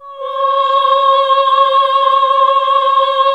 AAH C#3 -L.wav